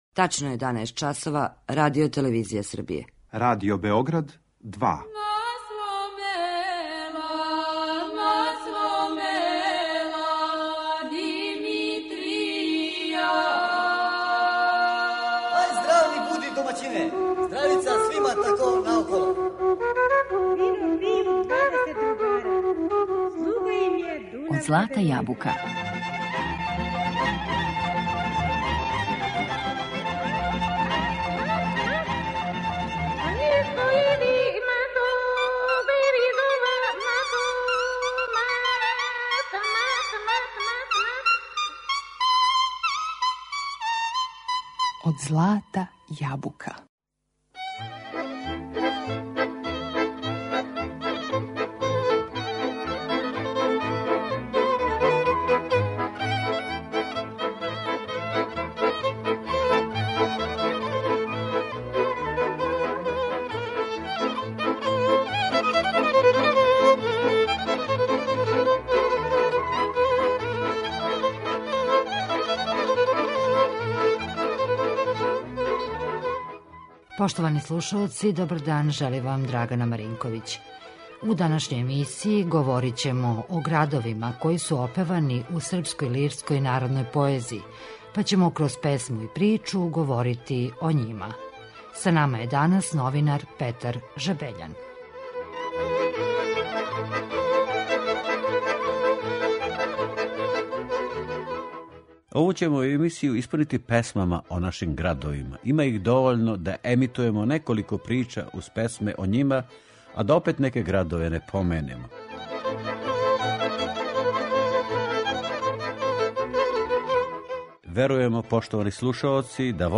Слушаћемо народне песме посвећене Београду, Новом Саду, Ужицу, Сомбору, Србобрану, Кикинди...